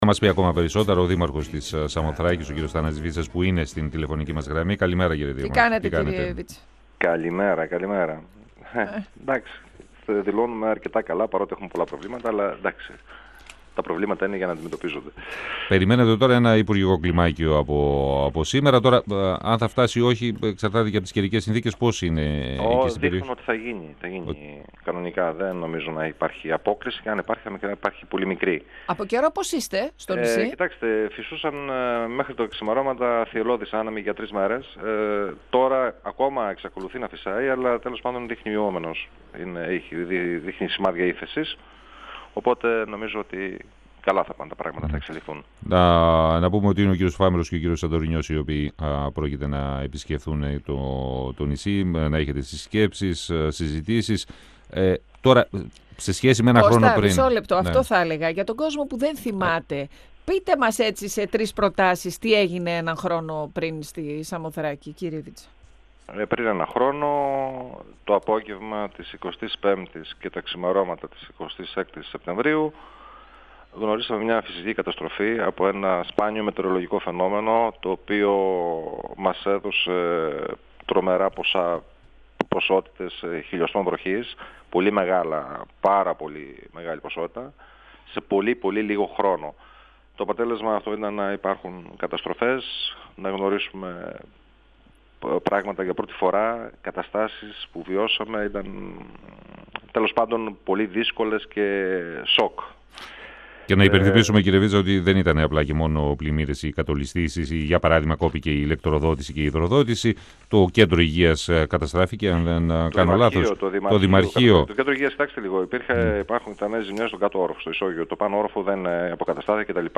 Ενας χρόνος συμπληρώθηκε από τις μεγάλες καταστροφές που προκλήθηκαν στη Σαμοθράκη από τα έντονα καιρικά φαινόμενα και το νησί επισκέπτονται οι αναπληρωτές υπουργοί Περιβάλλοντος και Ενέργειας, Σωκράτης Φάμελλος και Ναυτιλίας και Νησιωτικής Πολιτικής, Νεκτάριος Σαντορινιός. Υπάρχουν αρκετά ζητήματα ανοικτά επισήμανε ο δήμαρχος της Σαμοθράκης, Αθανάσιος Βίτσας μιλώντας στον 102FM του Ραδιοφωνικού Σταθμού Μακεδονίας της ΕΡΤ3 και αναφέρθηκε στον καθαρισμό των ρεμάτων, τη διαχείριση λυμάτων και απορριμμάτων καθώς και στις ακτοπλοϊκές συνδέσεις.
Συνεντεύξεις